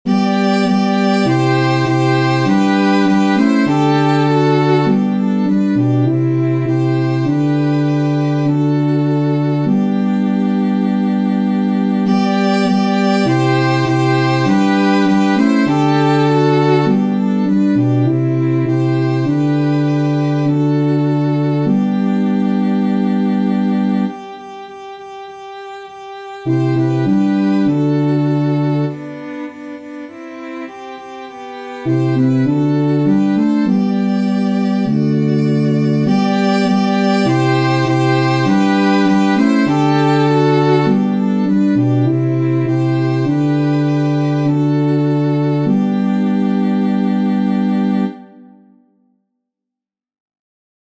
Acclamation du dimanche de l'assomption de la vierge Marie
• Catégorie : Chants d’Acclamations.